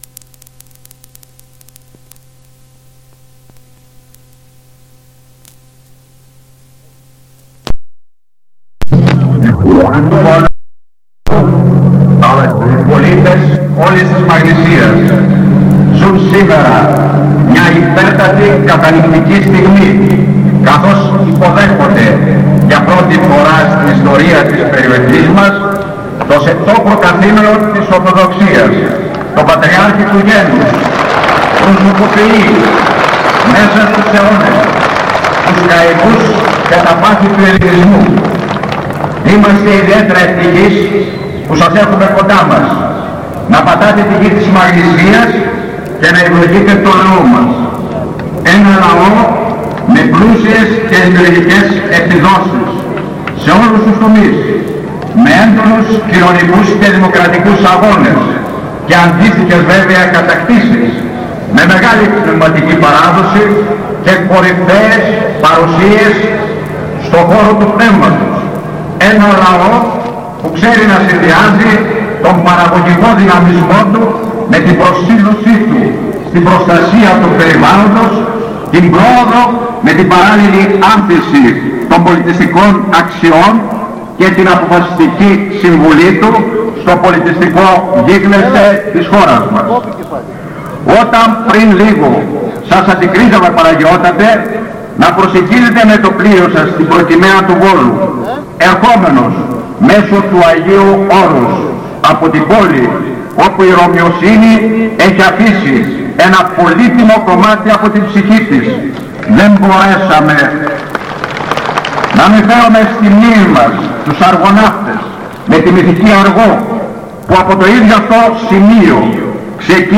Από την τελετή υποδοχής, ακούγονται ο τότε Δήμαρχος Βόλου Μιχ. Κουντούρης (προσφώνηση) και ο Πατριάρχης Δημήτριος (αντιφώνηση).